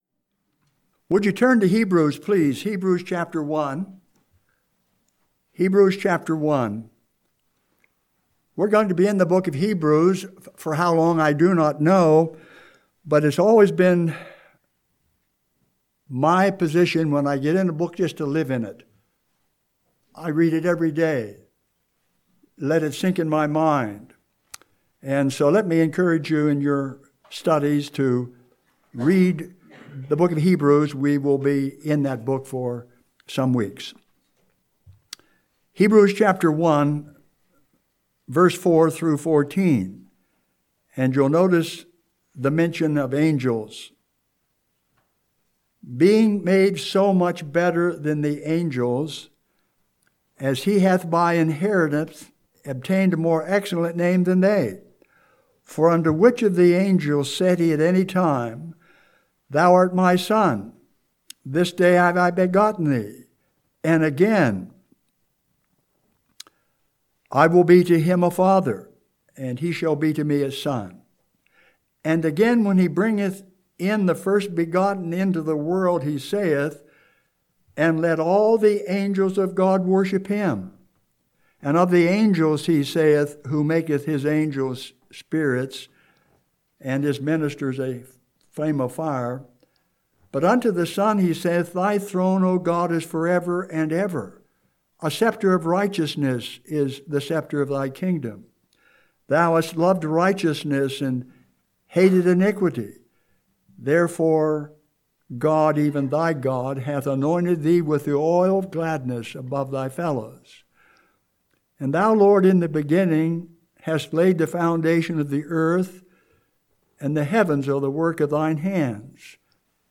Home › Sermons › January 19, 2020